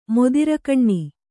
♪ modirakaṇṇi